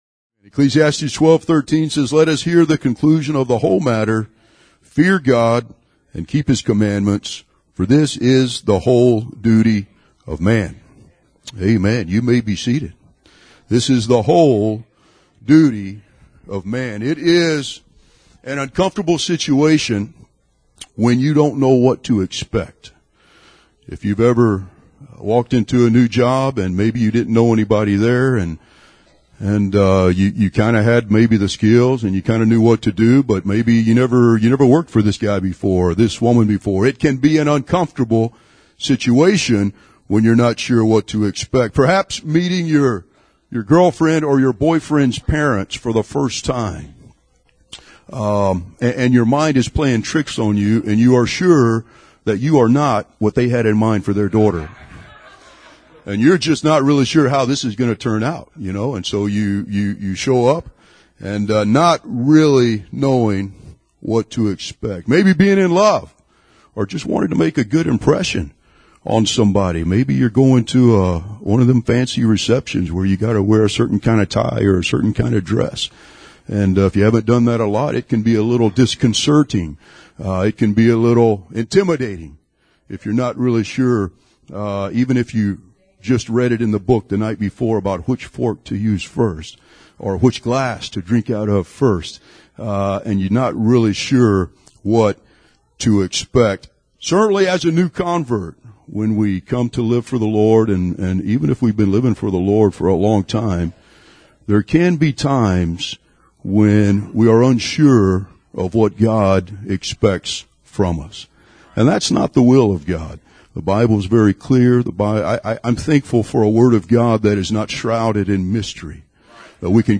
Apostolic Preaching